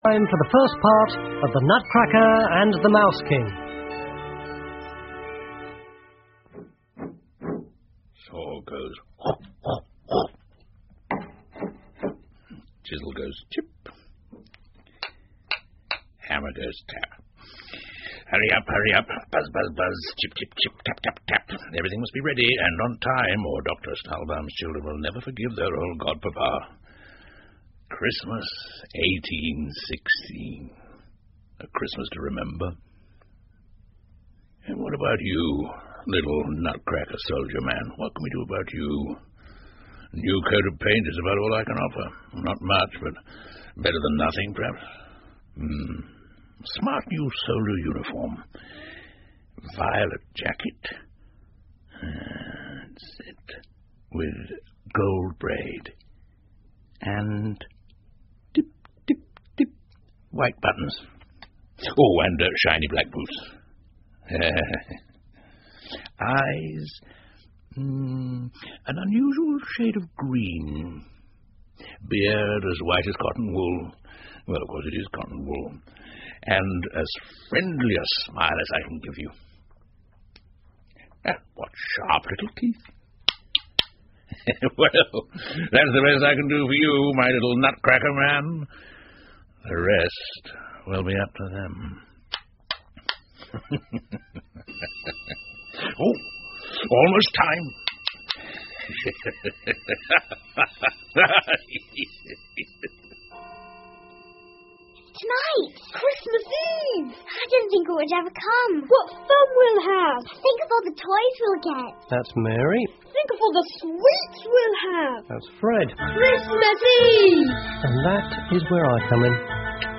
胡桃夹子和老鼠国王 The Nutcracker and the Mouse King 儿童广播剧 1 听力文件下载—在线英语听力室